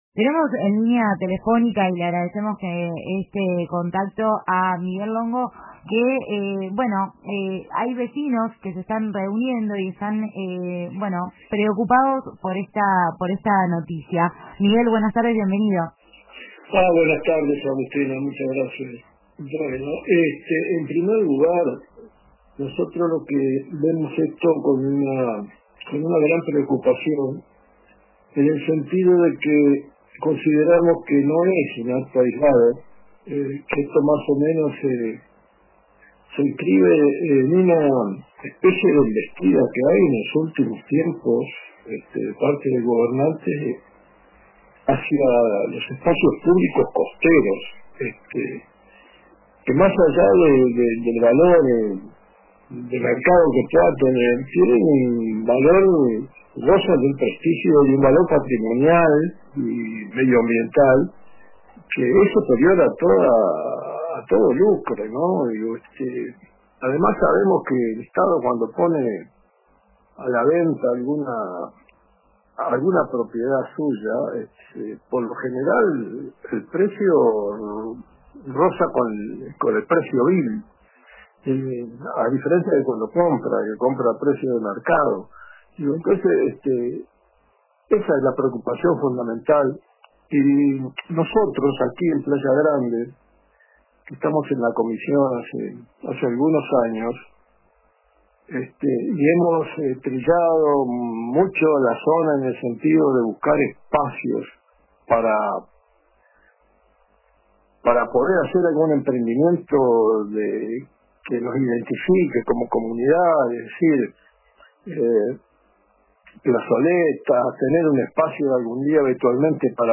habló en el programa La Tarde de RBC sobre la posible venta de la Plaza de la Madre, ubicada frente a la Rambla de los Argentinos.